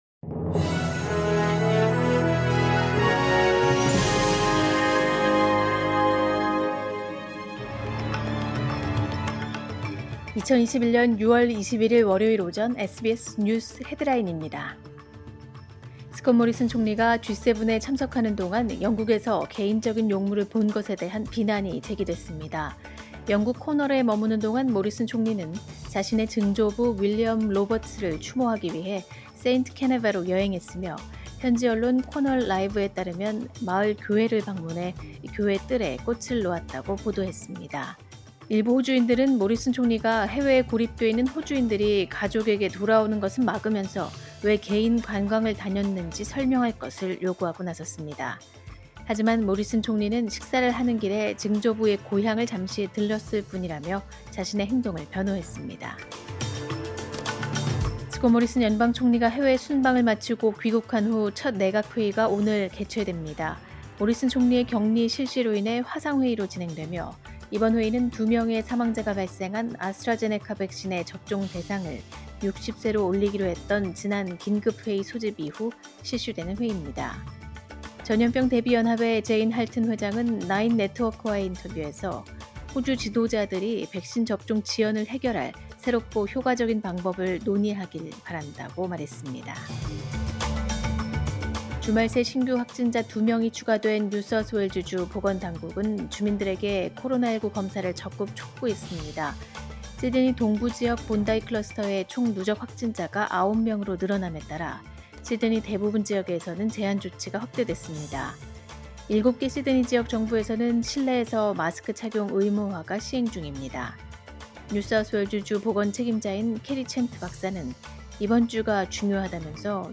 2021년 6월 21일 월요일 오전의 SBS 뉴스 헤드라인입니다.